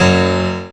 55y-pno11-c#4.wav